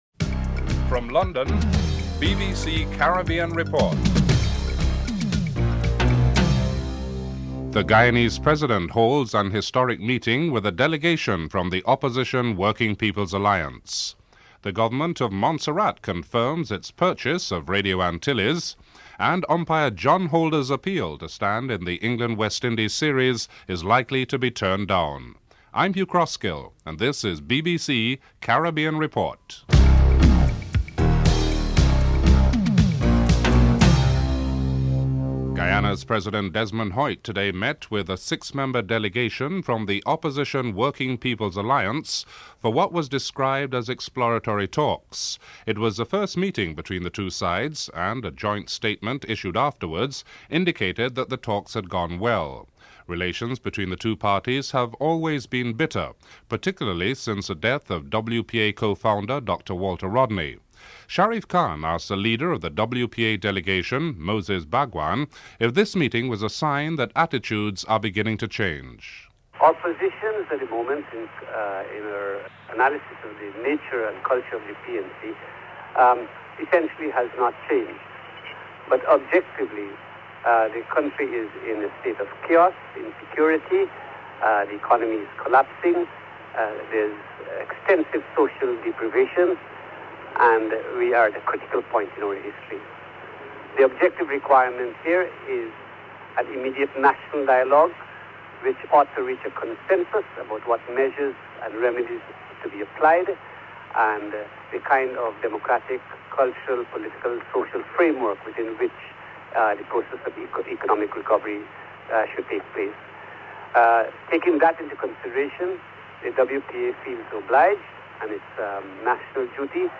1. Headlines (00:00-00:34)
4. Financial Market (09:05-09:49)